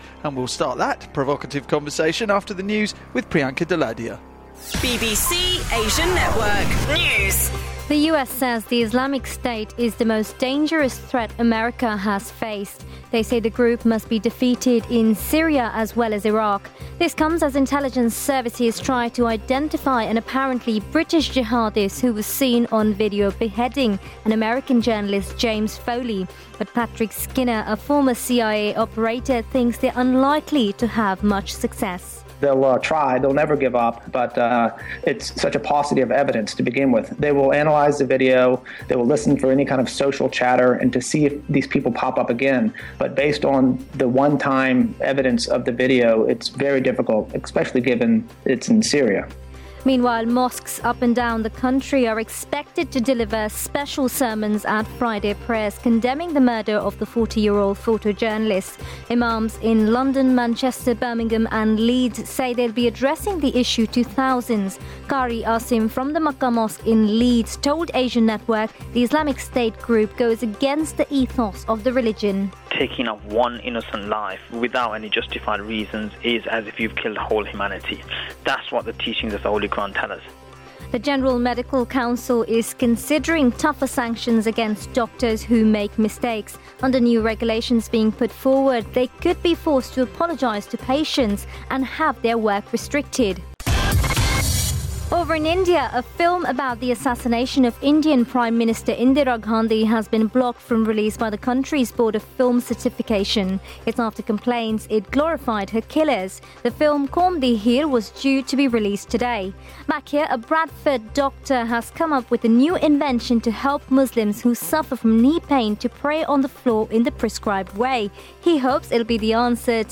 News bulletin
Bulletin reading sample on BBC Asian Network.